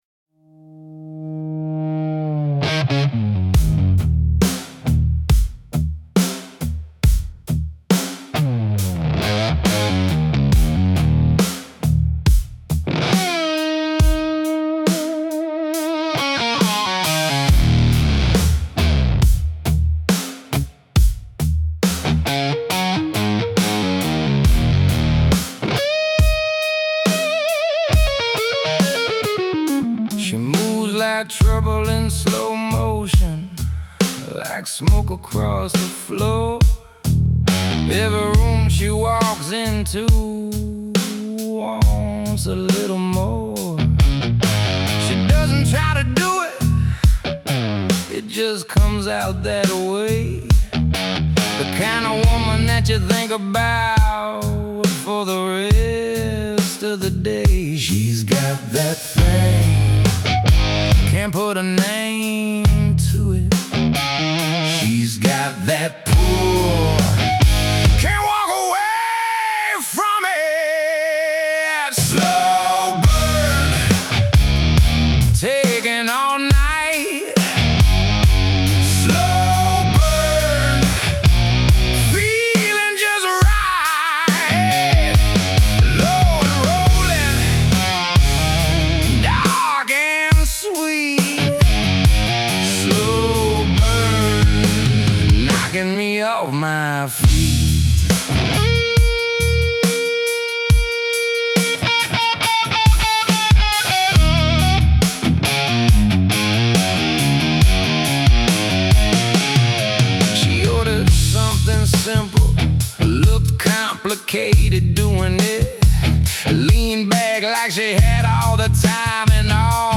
Made with Suno
blues rock, groove rock, blues